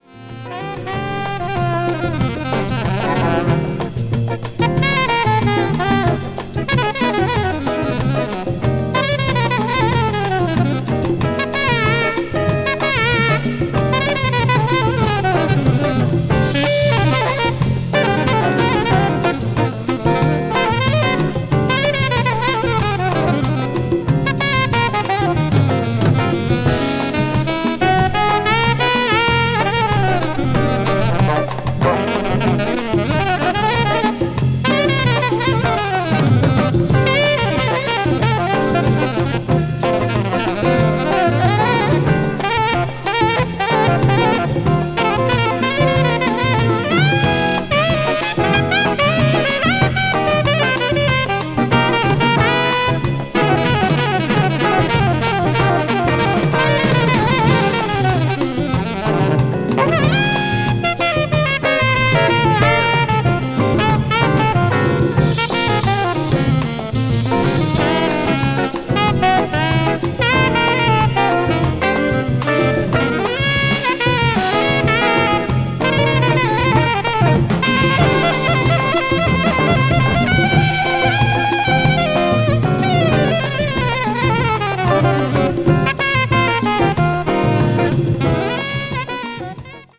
saxophone
a lively Brazilian bossa rhythm